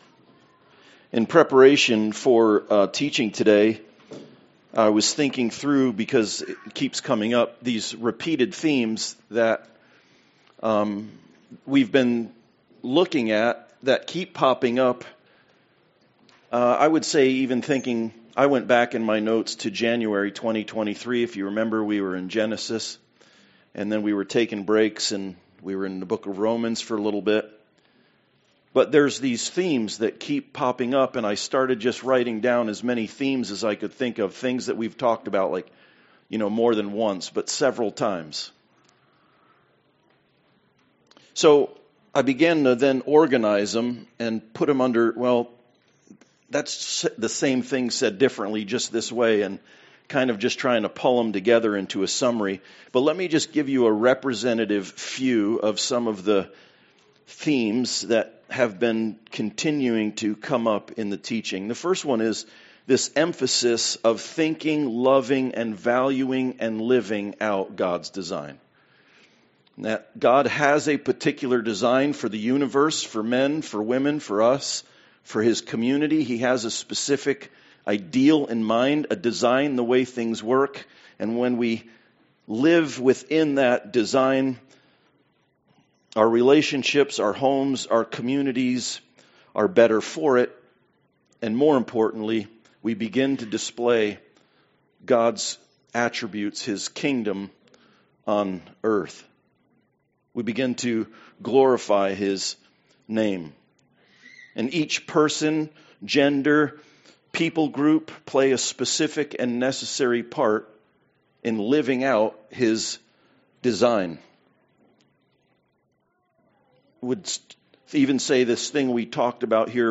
Nehemiah 4:15-23 Service Type: Sunday Service Kingdom building requires certain qualities in those who participate.